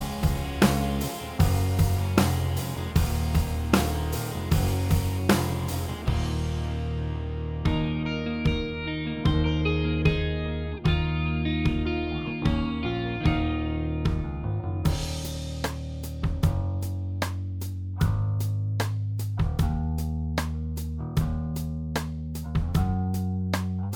Minus Acoustic Guitars Rock 4:06 Buy £1.50